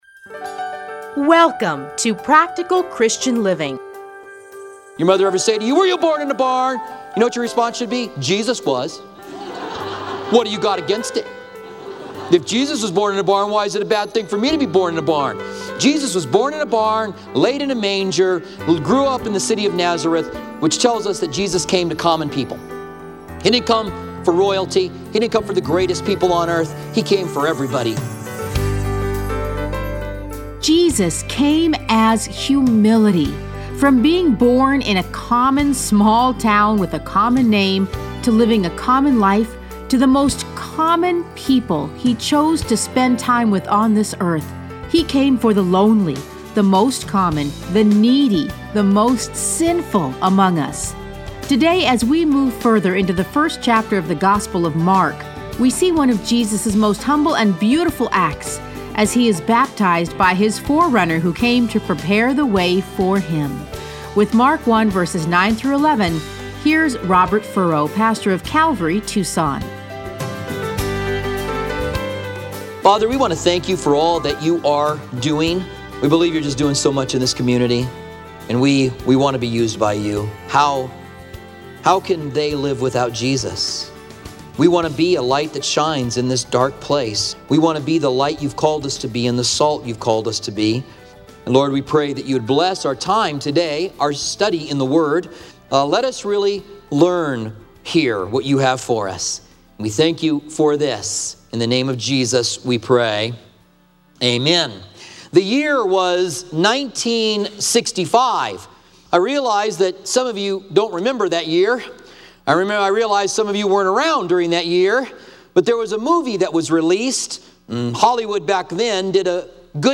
Listen to a teaching from Mark 1:9-11.